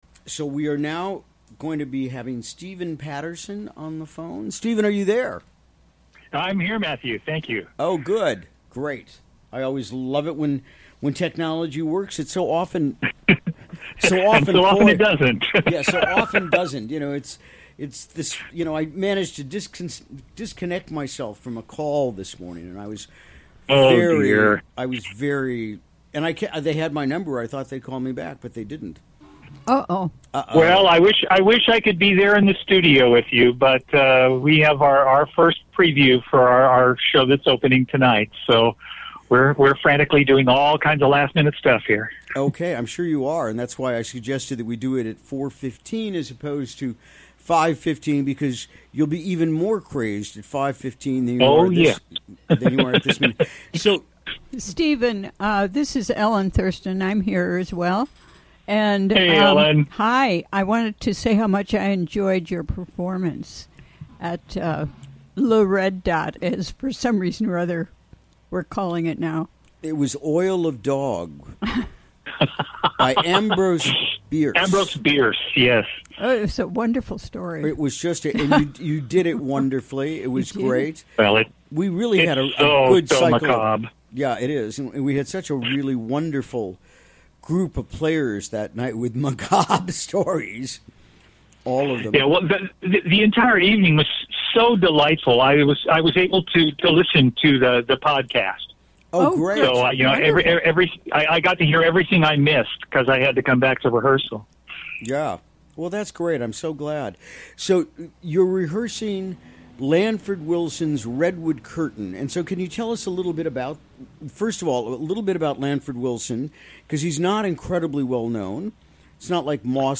Recorded during the WGXC Afternoon Show on Thursday, November 2, 2017.